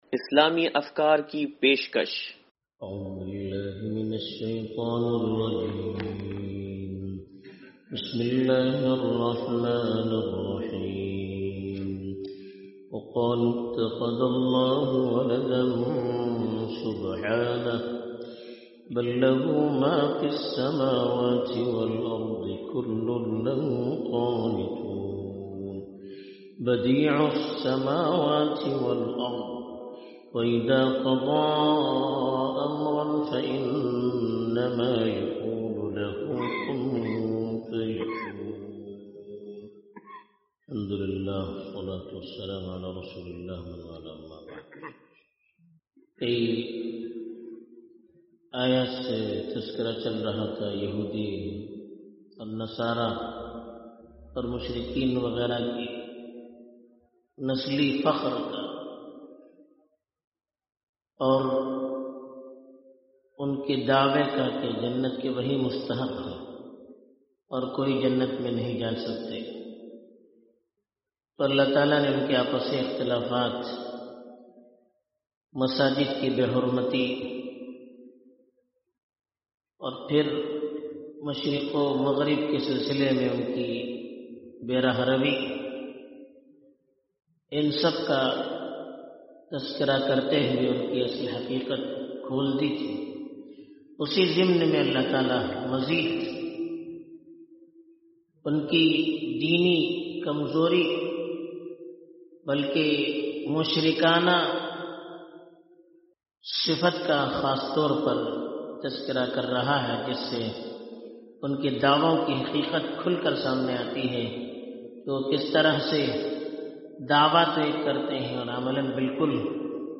درس قرآن نمبر 0082
درس-قرآن-نمبر-0082-2.mp3